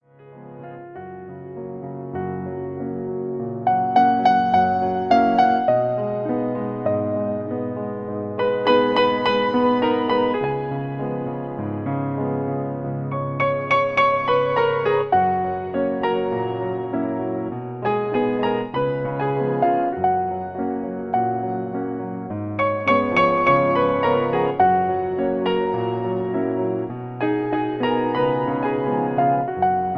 Popular african song